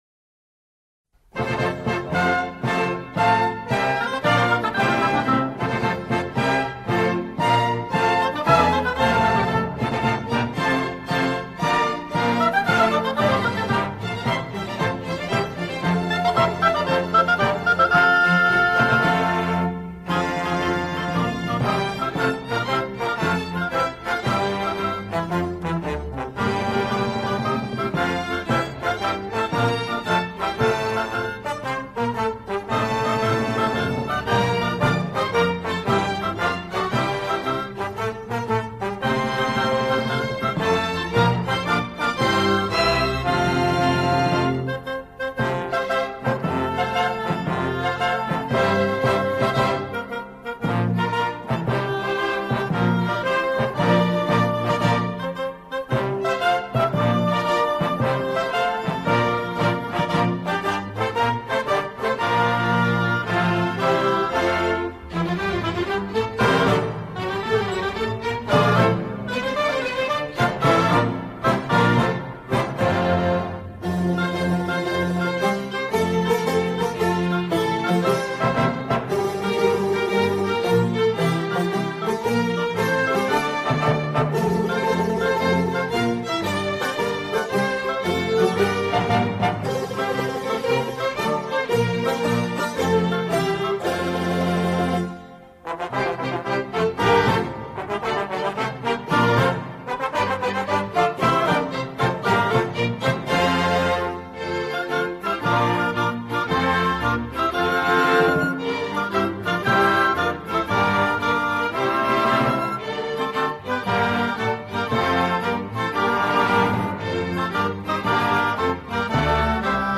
سرودهای دهه فجر
بی‌کلام